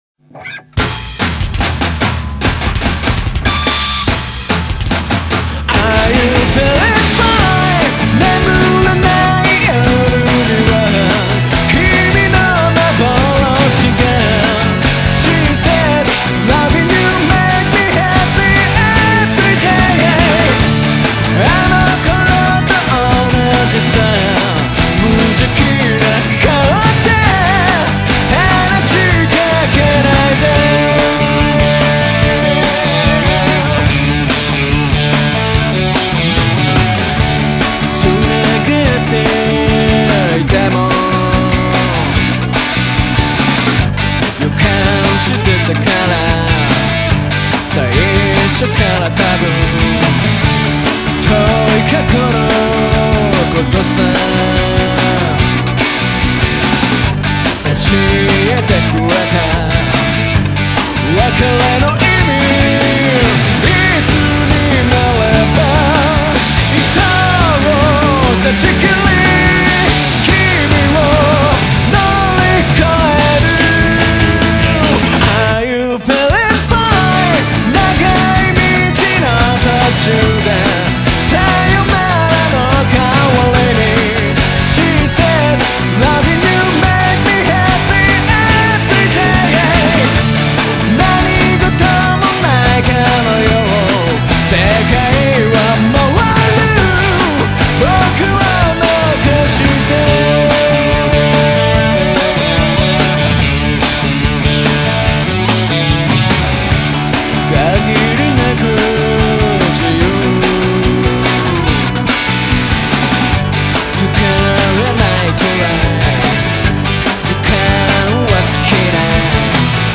jROCK